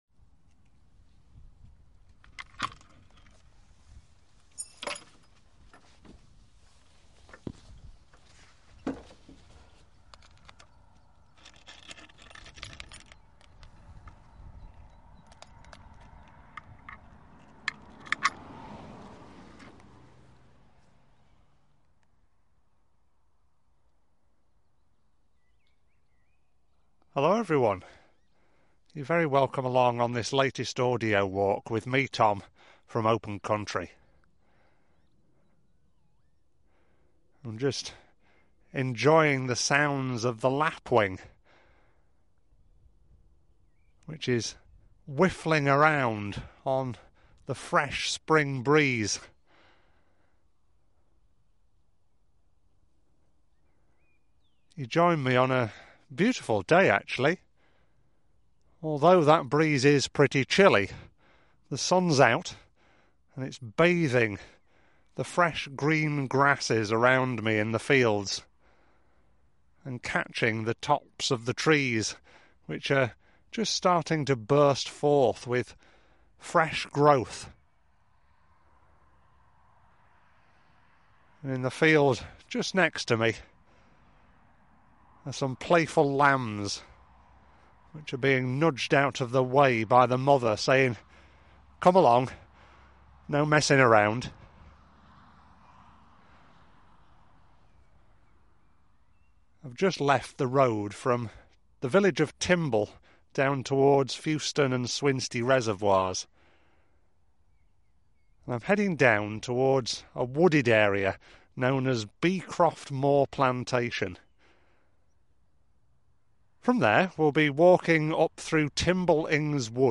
Timble Ings Wood Audio Walk
Timble-Ings-Wood-Lipersley-Pike-Audio-Walk.mp3